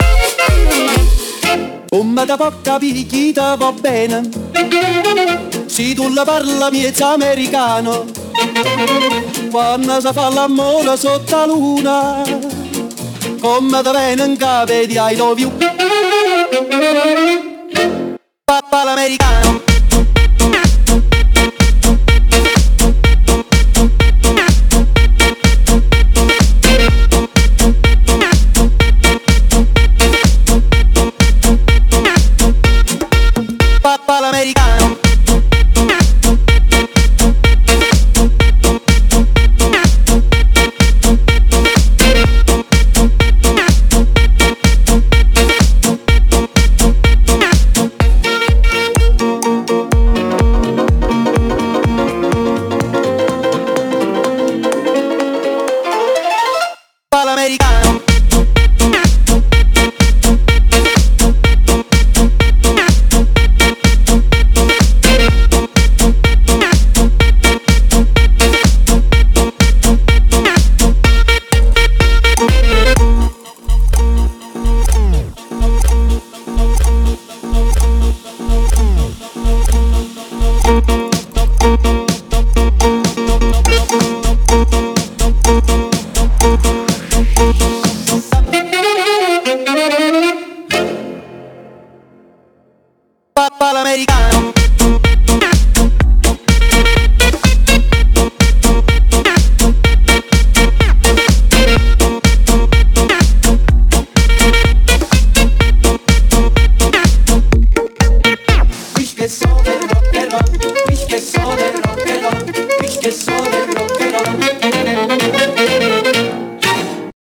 BPM: 125